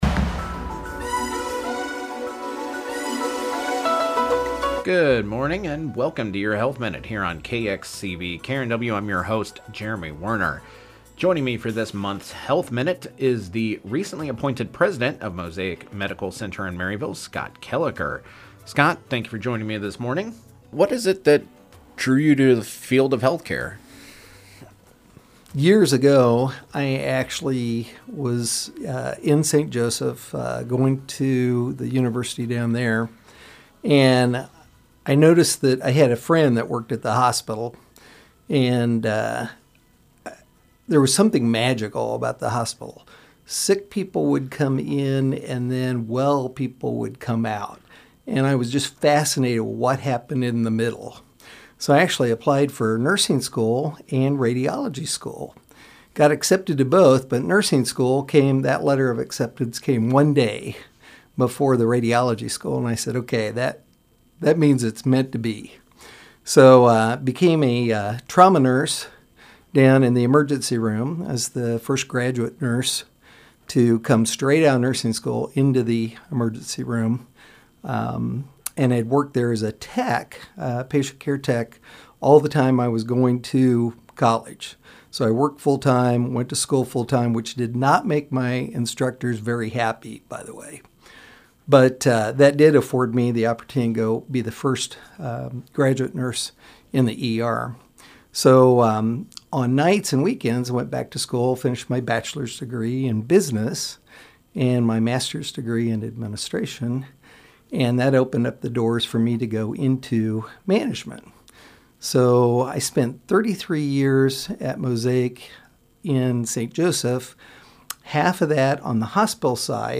Local Programming